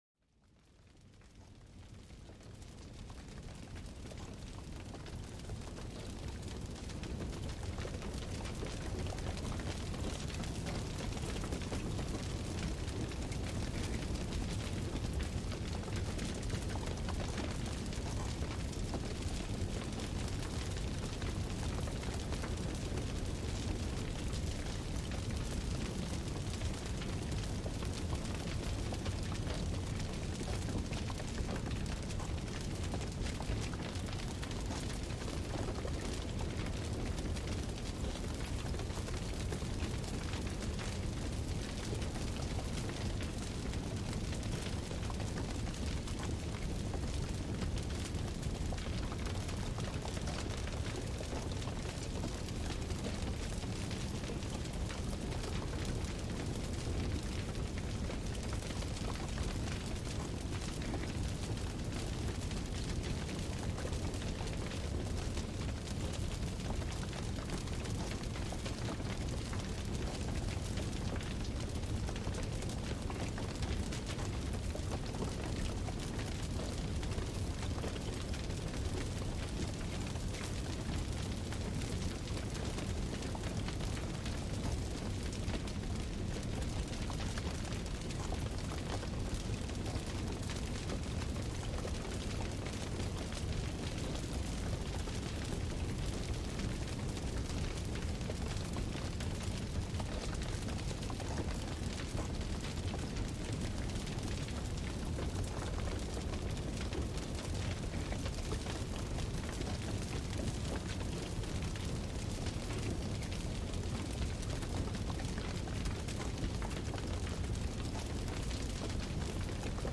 En este episodio especial, déjate envolver por el suave crepitar del fuego, un sonido tanto íntimo como universal. Exploramos cómo esta sinfonía natural de crujidos y calor puede transformar un momento ordinario en una experiencia de puro bienestar.